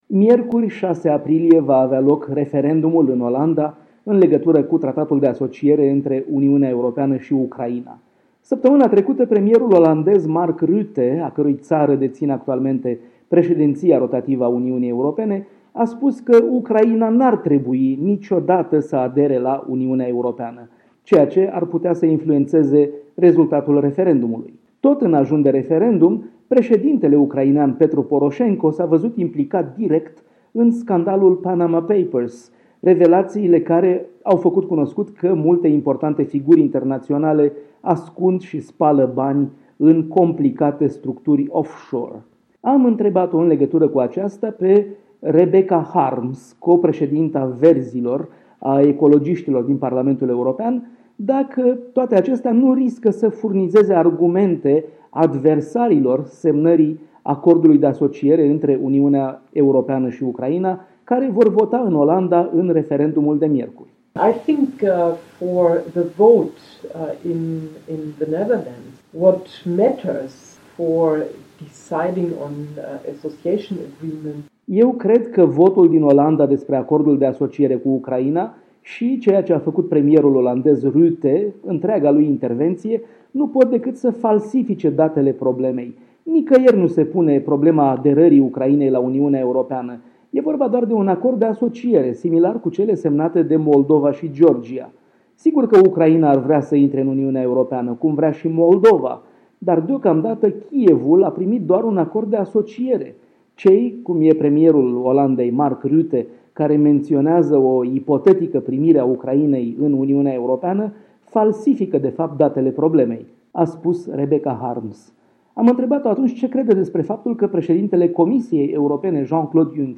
Embed Răspândește Interviu cu Rebecca Harms de Europa Liberă Moldova Embed Răspândește Codul a fost copiat Codul URL a fost copiat Trimite pe Facebook Trimite pe Twitter Nici o sursă media 0:00 0:03:58 0:00